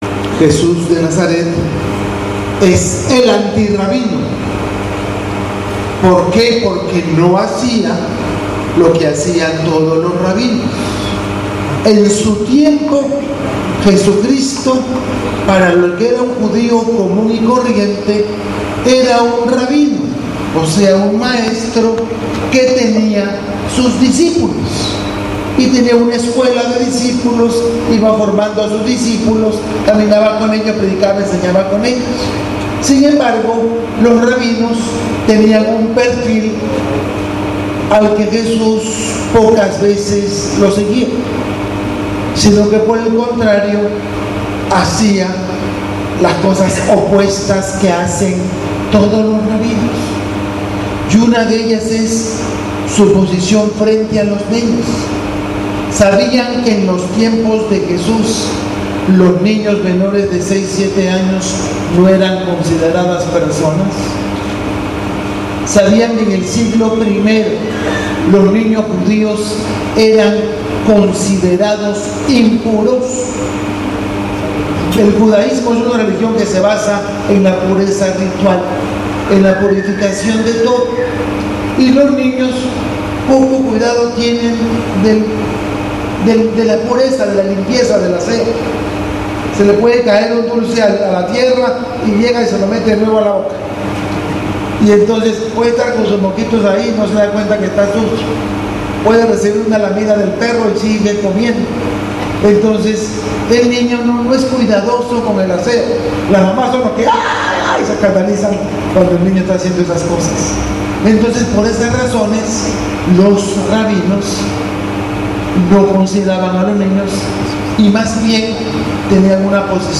Homilia del Sabado 02/03/19